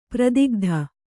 ♪ pradigdha